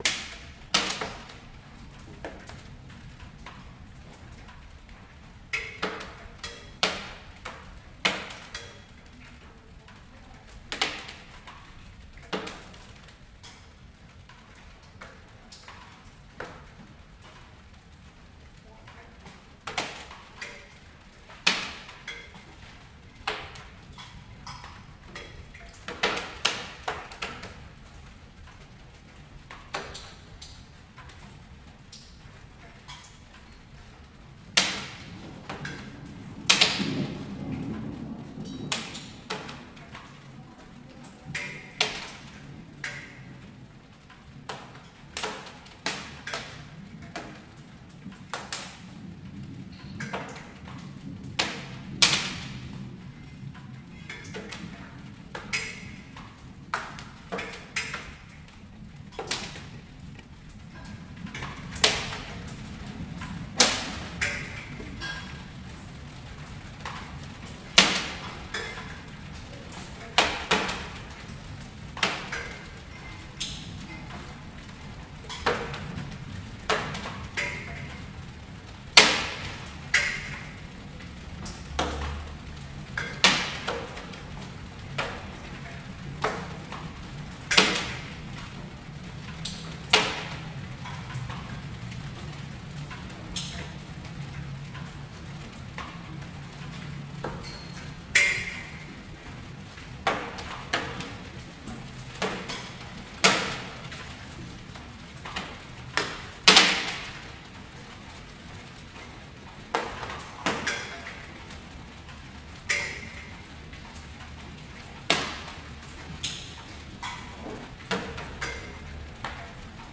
Recording made in: Carrer Del Comte Borrell, Barcelona, June 2014.